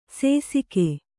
♪ sēsike